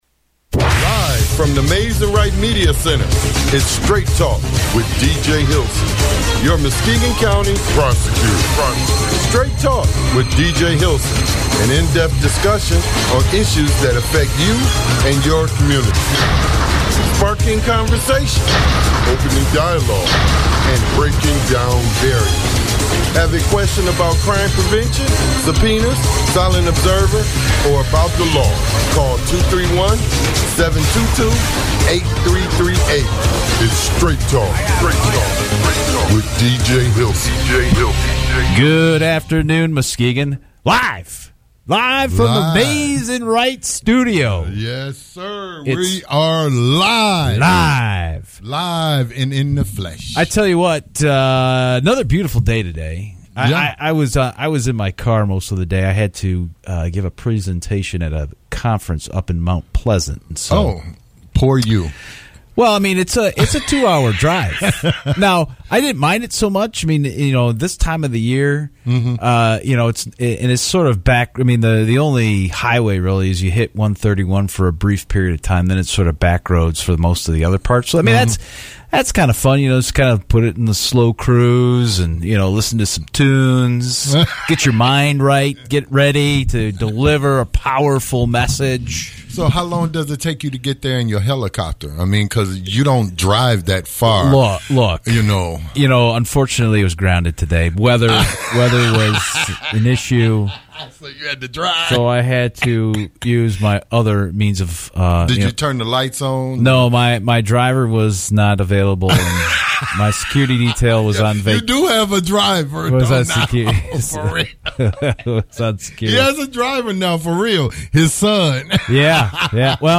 Show with Muskegon Prosecutor DJ Hilson